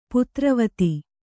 ♪ putravati